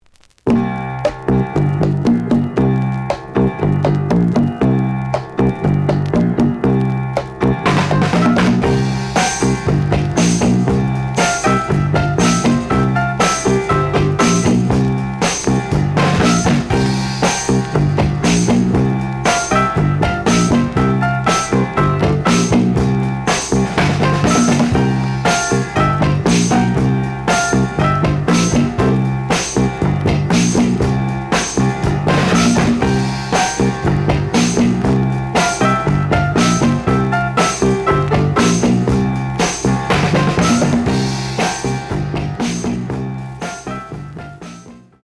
The audio sample is recorded from the actual item.
●Genre: Soul, 60's Soul
Some noise on parts of both sides.)